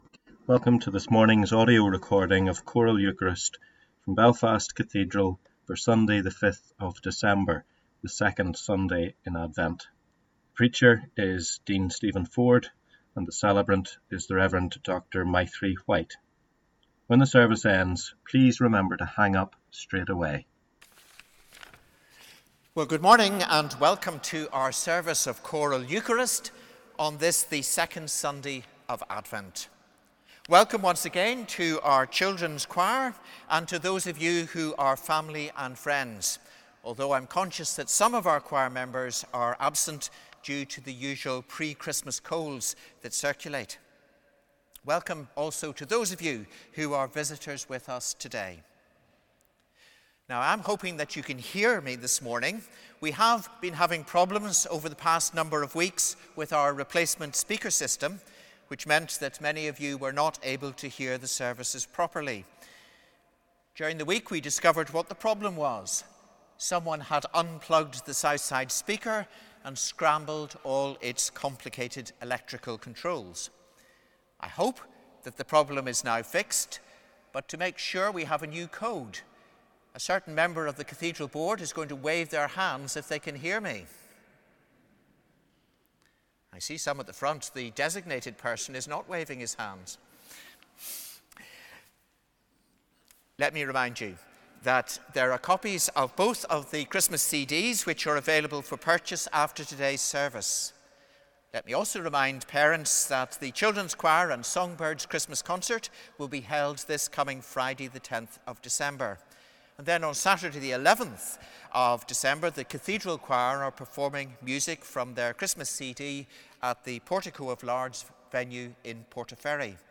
However, the spoken parts of the Eucharist can be accessed later in the day by clicking on the link at the foot of this page.